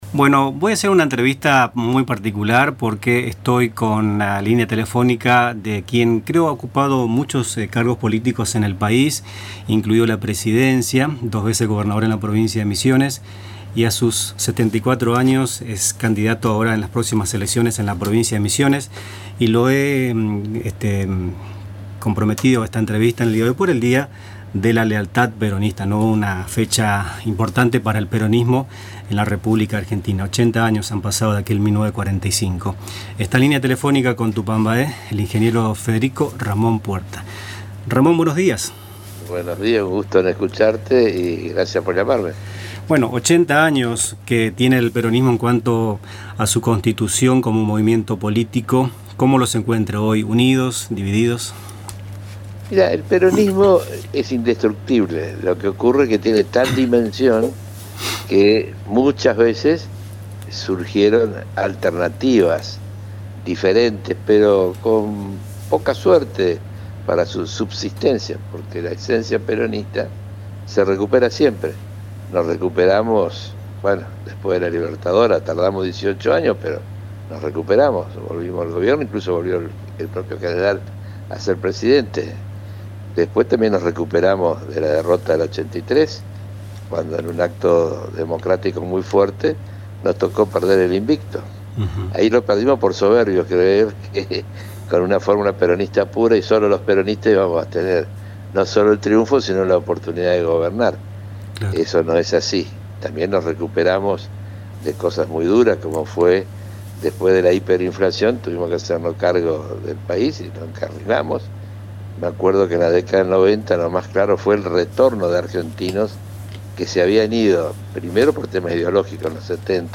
A sus 74 años, el ingeniero Federico Ramón Puerta ofreció una entrevista a Radio Tupambaé, donde analizó la situación actual del peronismo, la política y la economía del país, y presentó los lineamientos de su propuesta electoral de cara al 26 de octubre, fecha en la que se renovarán bancas legislativas nacionales.